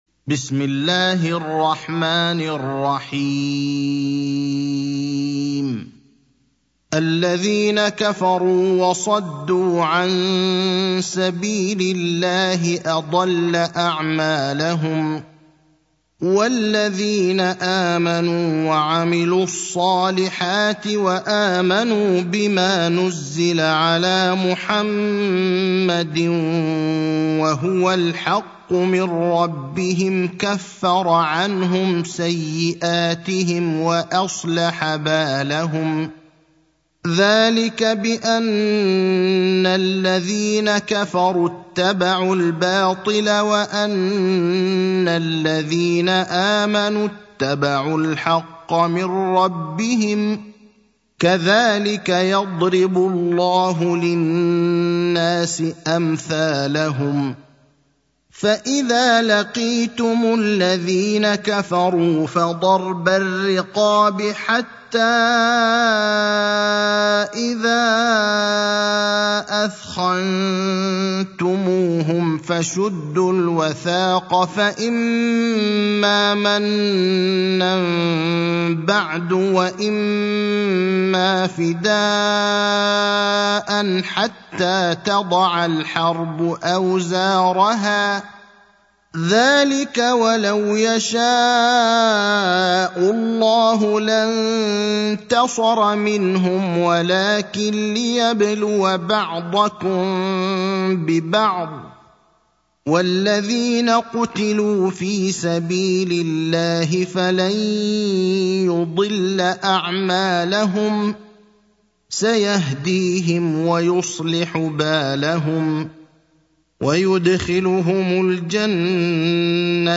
المكان: المسجد النبوي الشيخ: فضيلة الشيخ إبراهيم الأخضر فضيلة الشيخ إبراهيم الأخضر محمد (47)